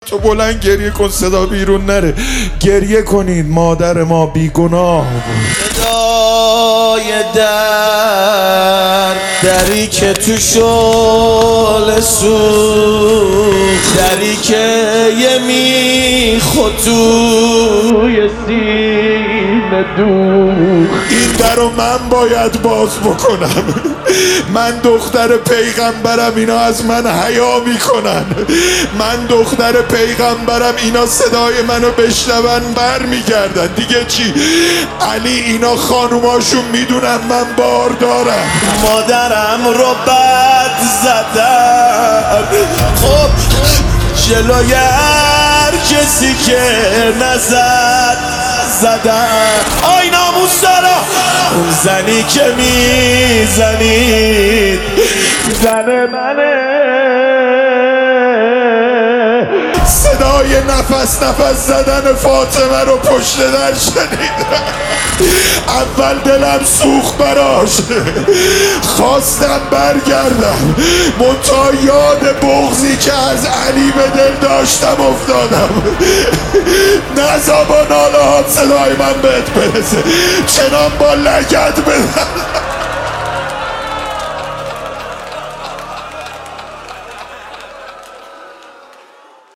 روضه فاطمیه